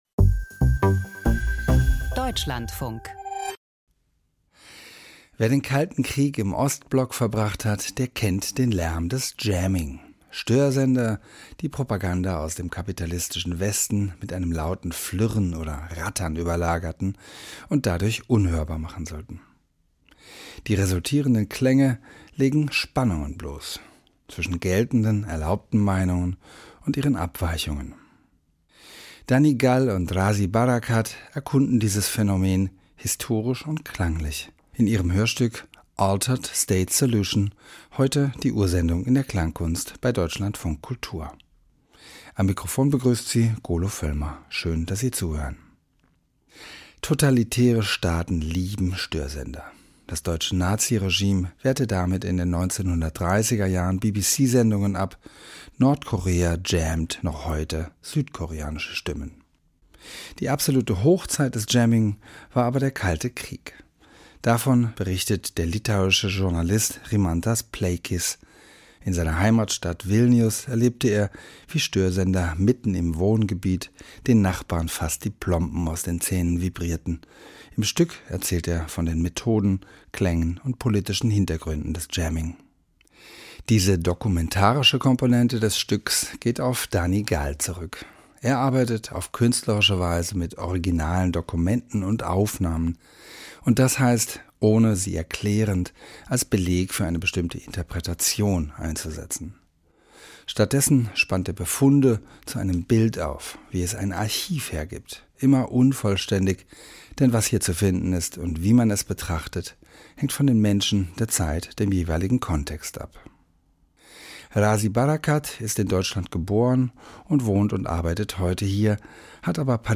Klangkunst: Der Gesang der Steine - Like a singing stone
Email Audio herunterladen • Sound Art • Steine existieren länger als jedes Leben auf der Erde: Wovon könnten sie berichten?